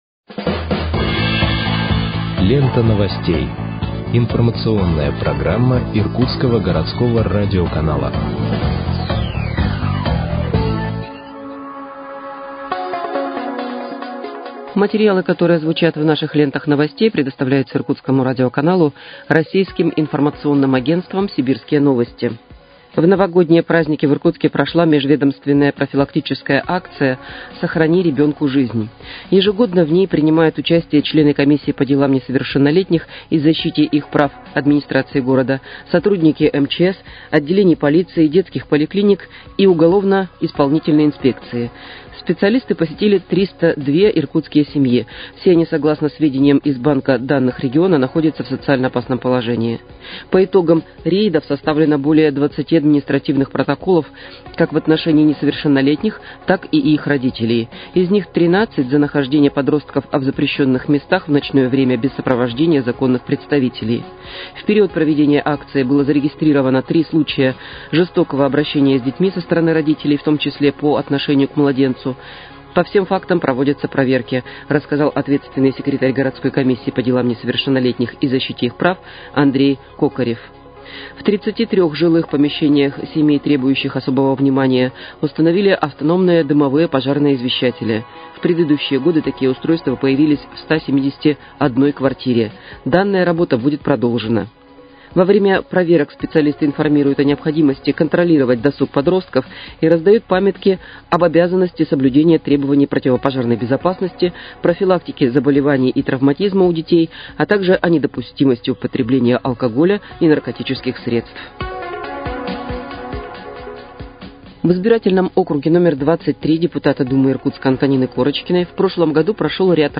Выпуск новостей в подкастах газеты «Иркутск» от 20.01.2025 № 2